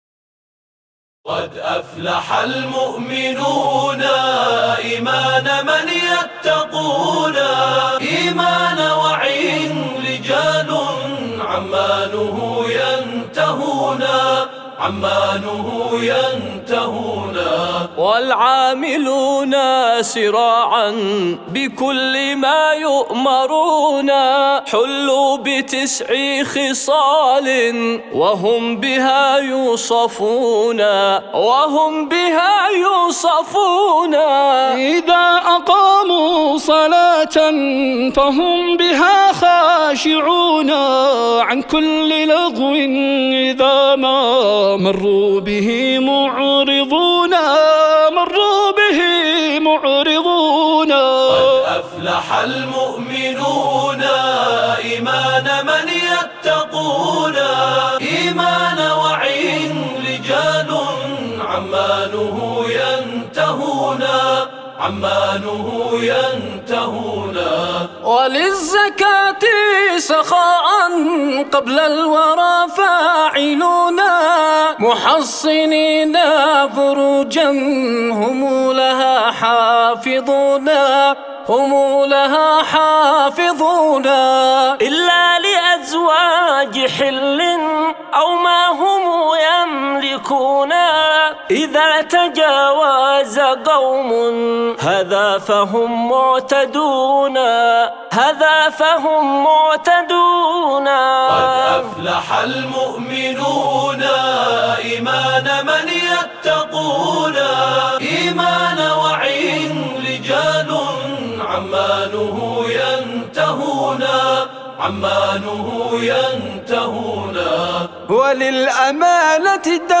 mp3 بدون موسيقى